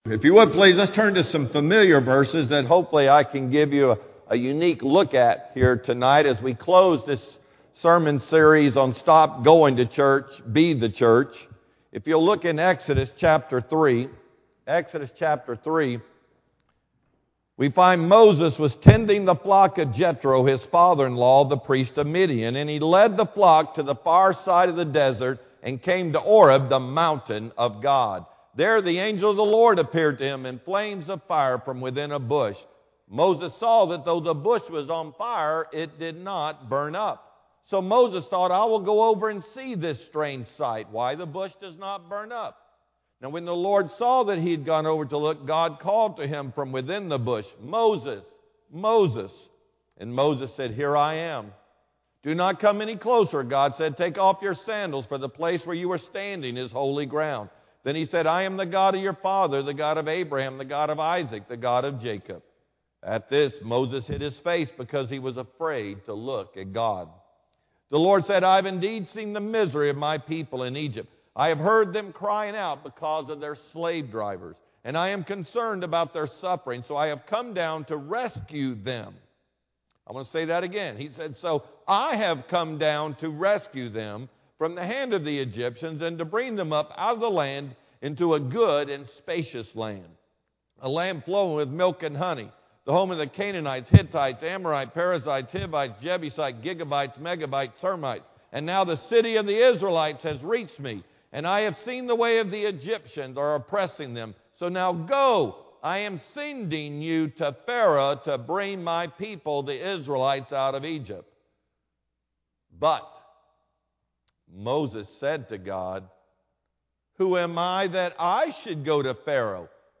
Sermon-Feb-1-2015-PM-CD.mp3